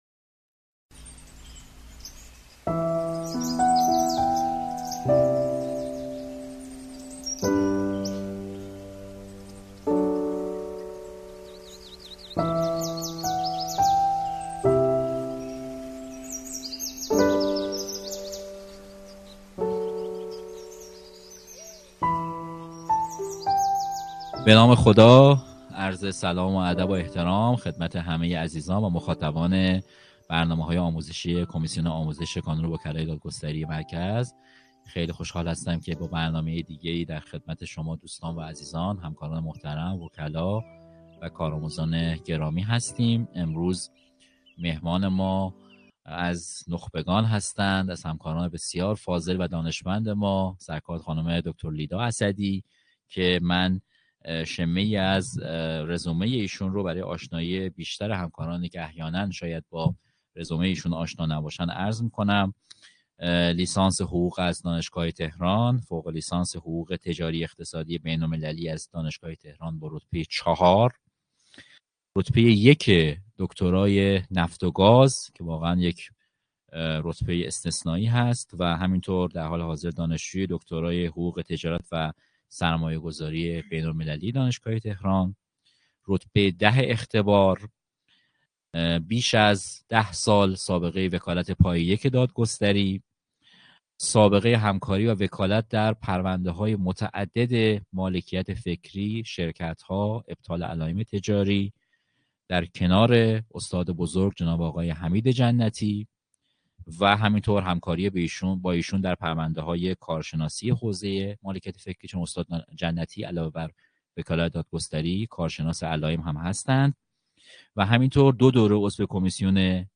در نشست آنلاین کمیسیون آموزش کانون وکلای دادگستری مرکز